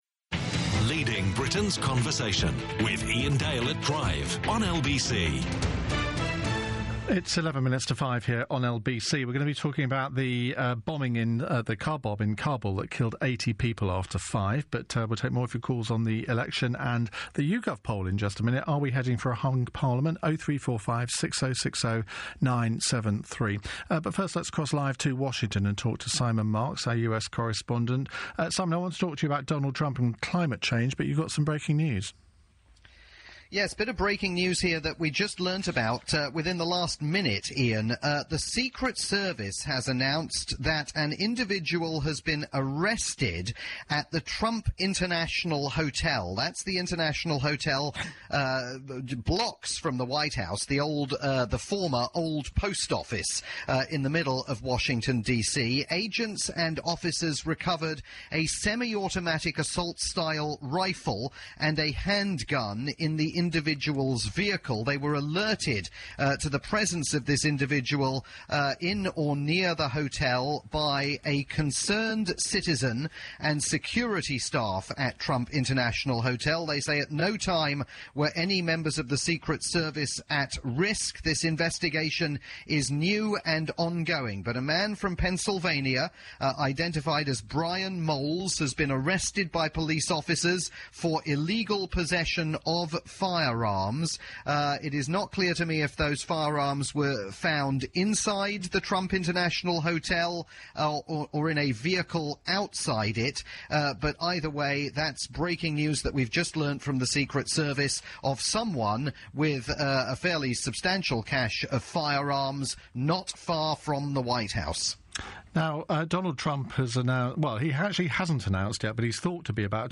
report from "Iain Dale at Drive" on the UK's LBC.